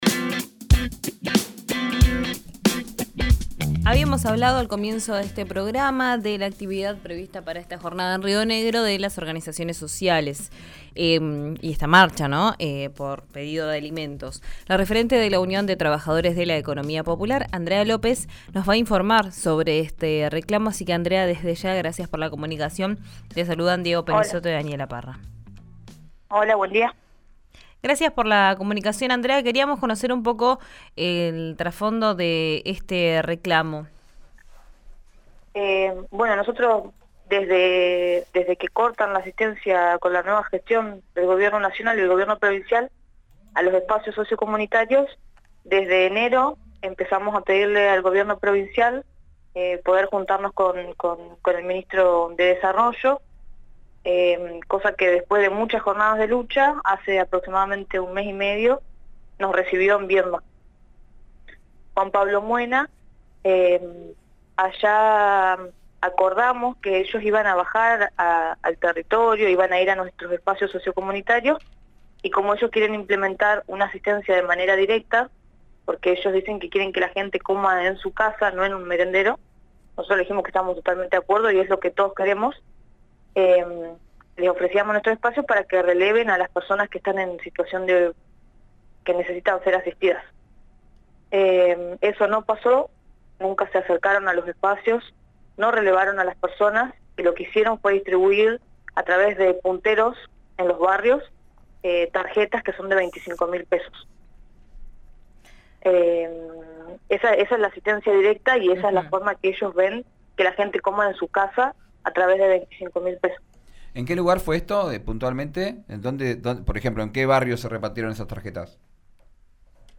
En dialogo con Río Negro RADIO